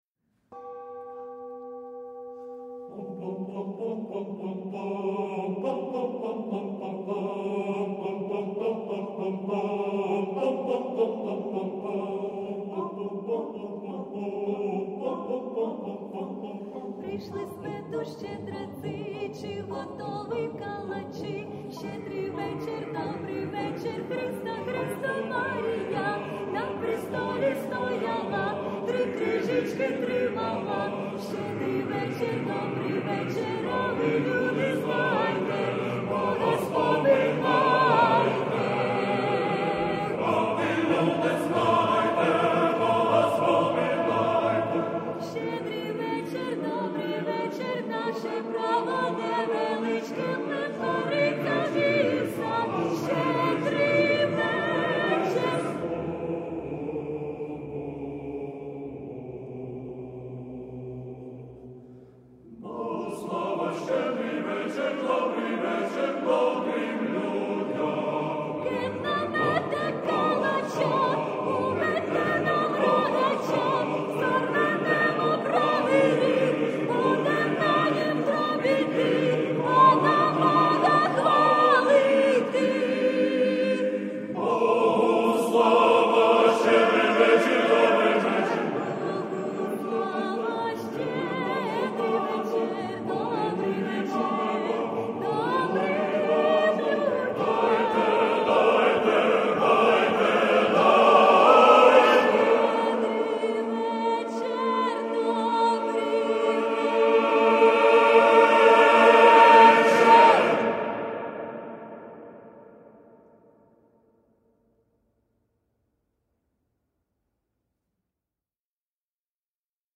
Різдв'яна щедрівка
(лемківська)